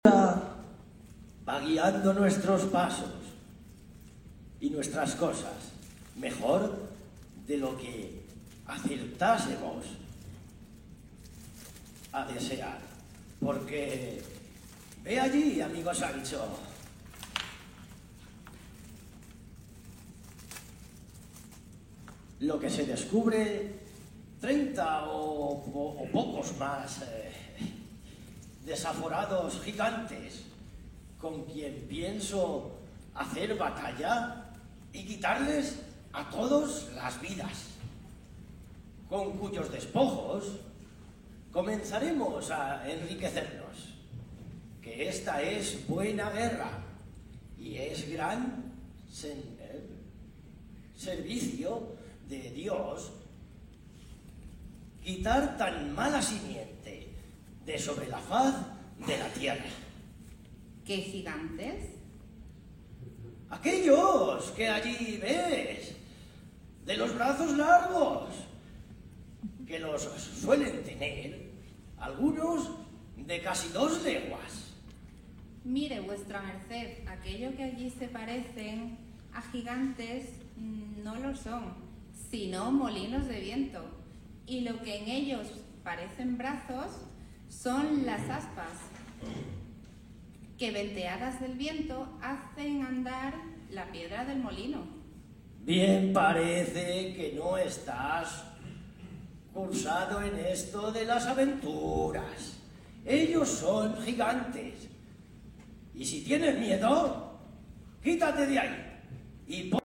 Además del ya tradicional tour táctil por el escenario a través de los decorados, instrumentos, vestuario y elementos del teatro, también se hizo una mención especial al braille, celebrando, además del 200 aniversario de este sistema de lectoescritura, el 100 aniversario de la primera edición en braille de la obra de Cervantes, “Don Quijote de la Mancha”, el famoso hidalgo que cobró voz en las tablas del teatro del Almagro en una demostración de teatro leído en braille.